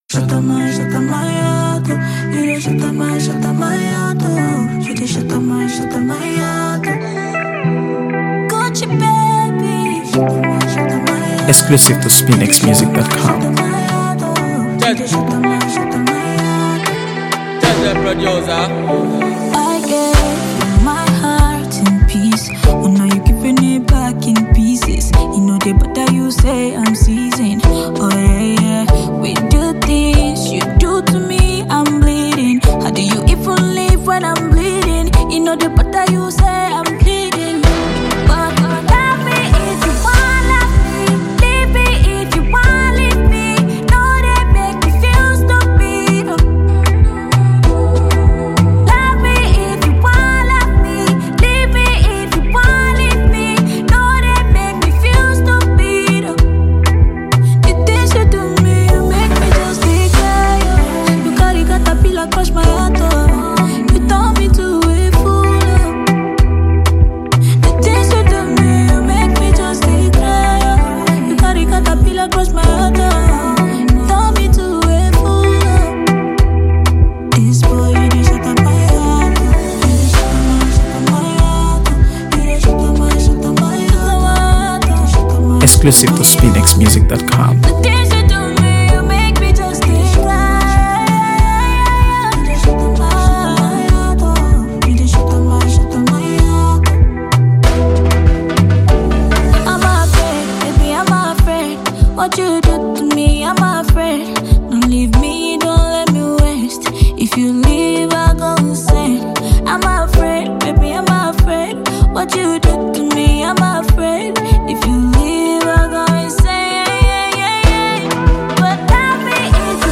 AfroBeats | AfroBeats songs
Nigerian singer and songwriter